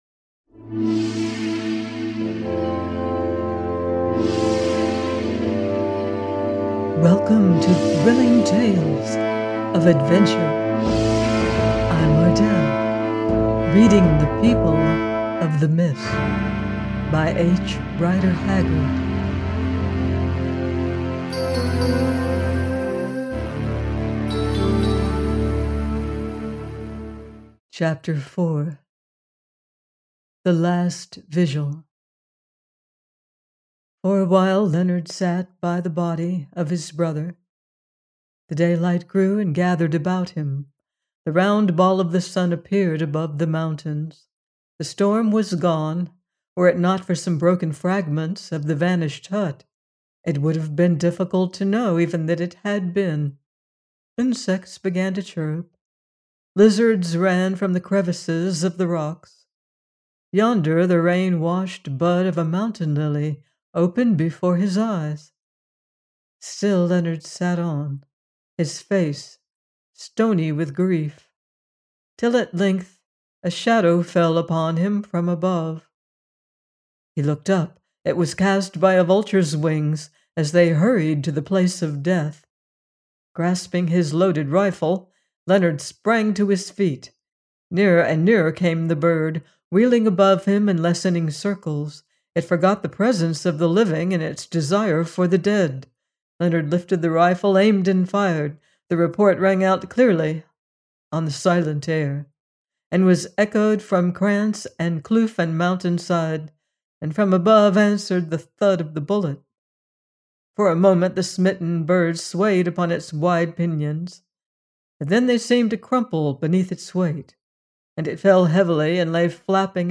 The People of the Mist- 4 : by H. Rider Haggard - audiobook